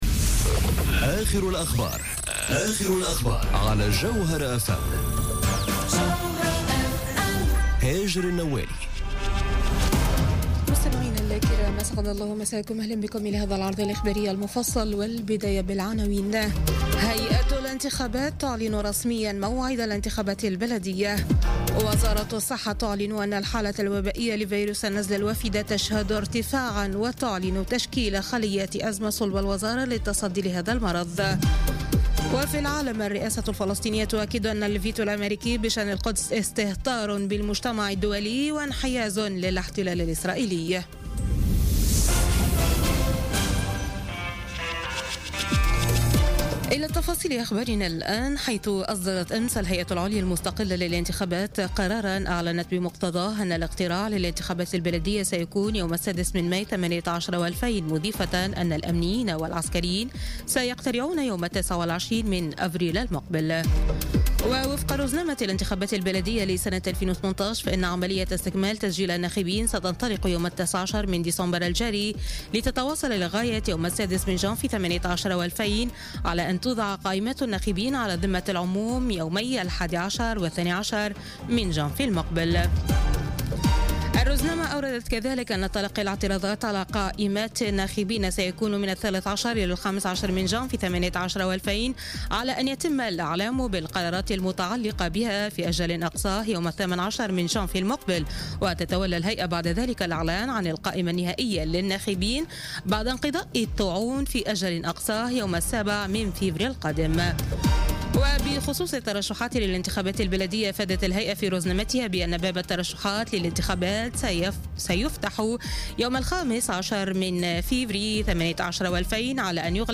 نشرة أخبار منتصف الليل ليوم الثلاثاء 19 ديسمبر 2018